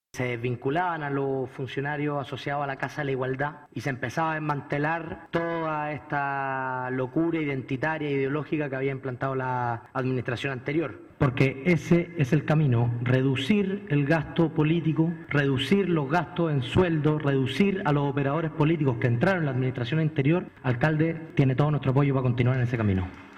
El concejal republicano, Vicente Martínez, agradeció que el alcalde esté reestructurando la municipalidad y lo emplazó a terminar de lleno con la ideología de género.